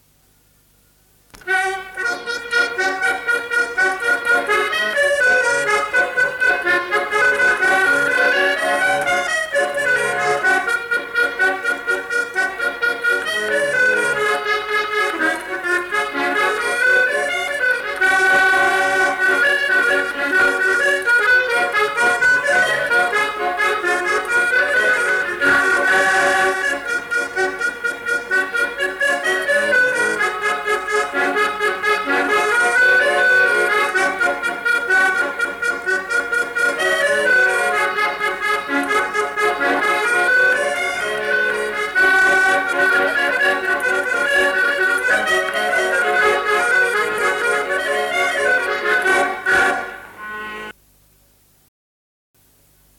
Instrumental. Accordéon diatonique.
Lieu : Mas-Cabardès
Genre : morceau instrumental
Instrument de musique : accordéon diatonique
Notes consultables : Le joueur d'accordéon n'est pas identifié. Il joue sans doute une figure de quadrille.